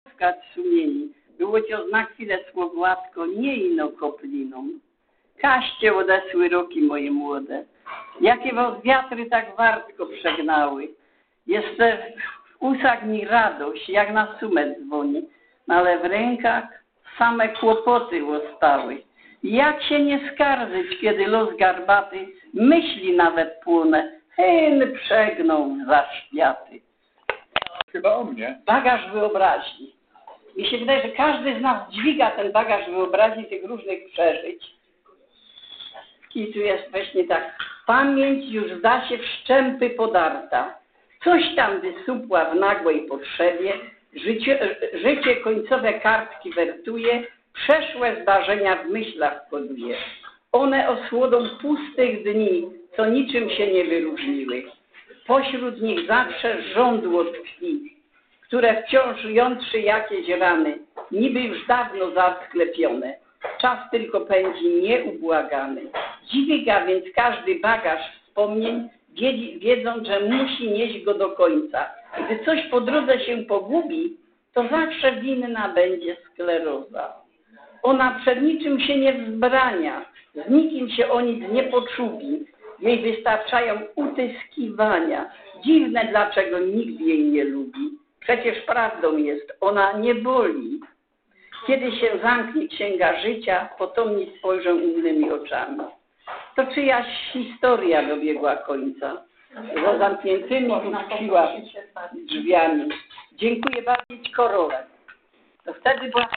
w siedzibie TMZŻ